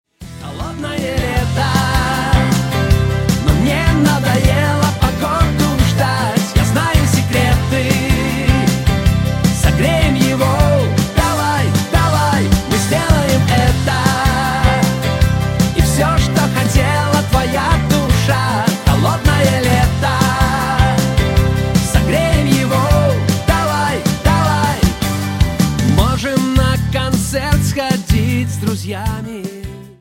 • Качество: 128, Stereo
поп
гитара
позитивные
мужской вокал
добрые